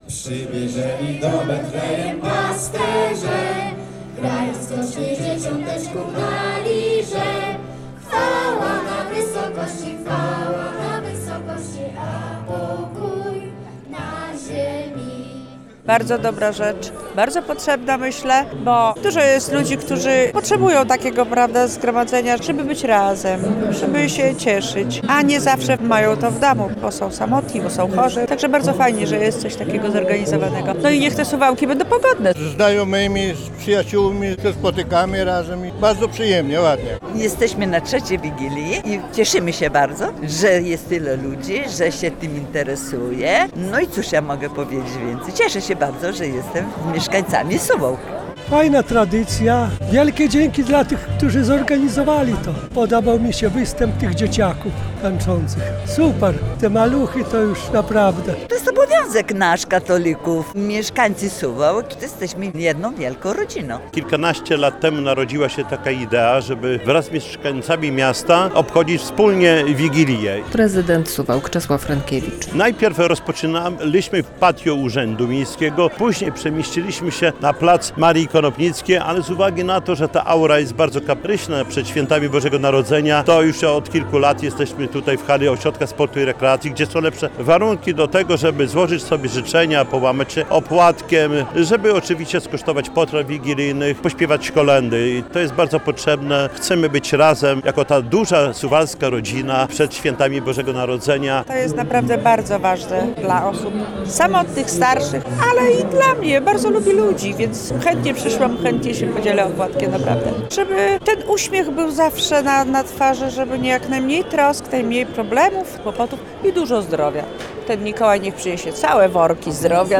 To okazja do złożenia świątecznych życzeń - mieszkańcy Suwałk spotkali się na wigilii miejskiej